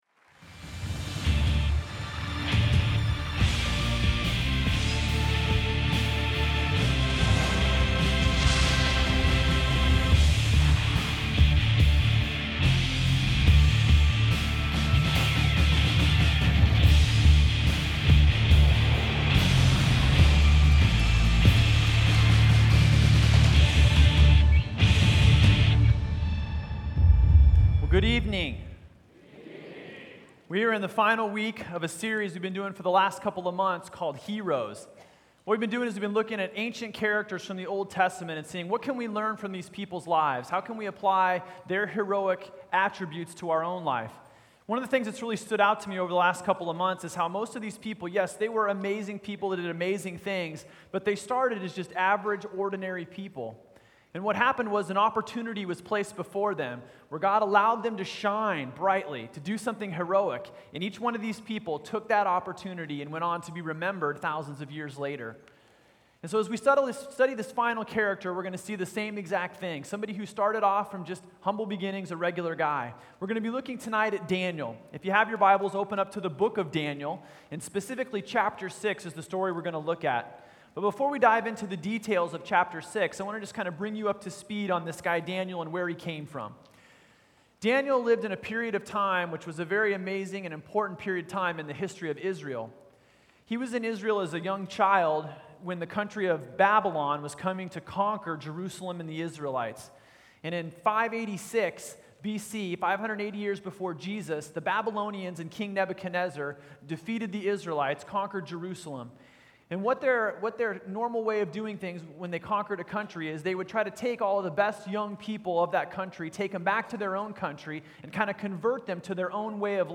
Other Messages in this Series Noah Abraham Joseph Moses Joshua David Elijah Nehemiah Esther Daniel Related Downloads Download Audio Download Sermon Note